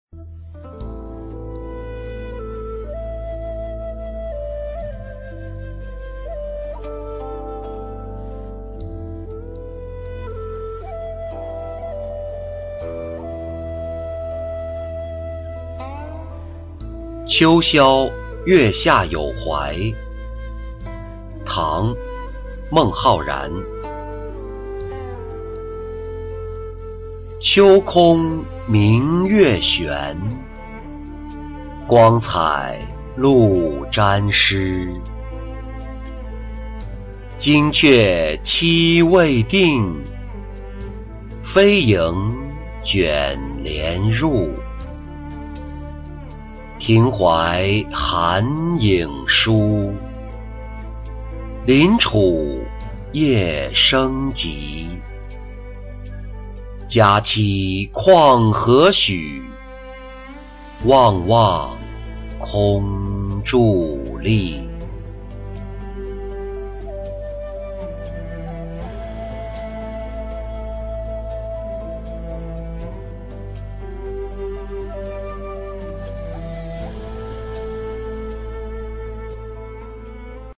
秋宵月下有怀-音频朗读